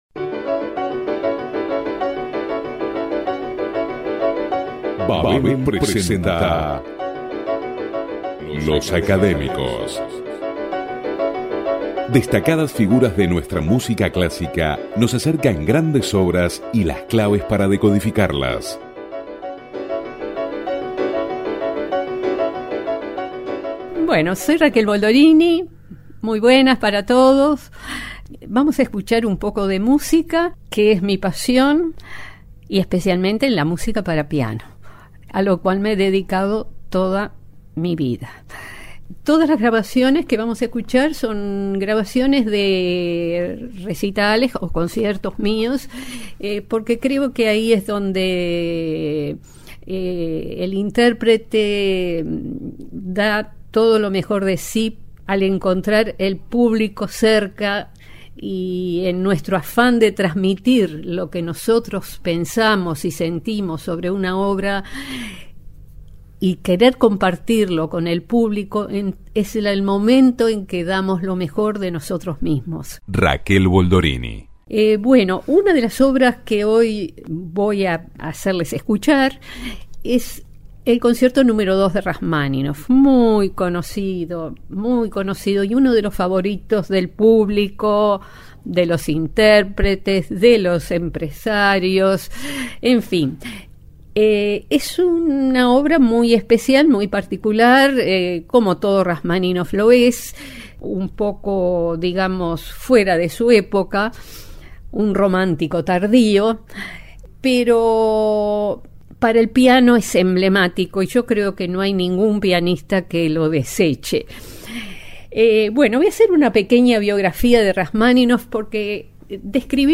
especialmente digitalizadas para este programa. Las presenta acompañadas de increíbles anécdotas: una inesperada interpretación del Concierto Nº2 para piano de Rachmaninov en el Teatro Colón y tres preludios de Claude Debussy, que estudiara en Paris con Marguerite Long (amiga personal del compositor) y grabara en Washington para la OEA.